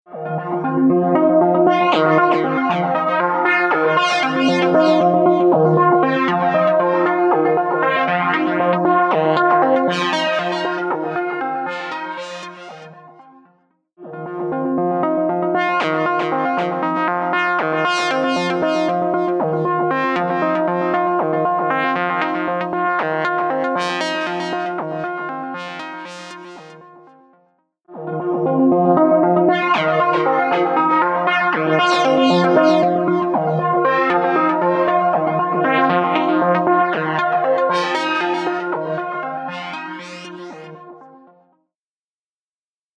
TriceraChorusは、サウンドに極上の豊かさと空間的な奥行きをもたらす、洗練されたコーラス・ソリューションです。
TriceraChorus | Synth | Preset: Syrupy Leslie
TriceraChorus-Eventide-Synth-Syrupy-Leslie.mp3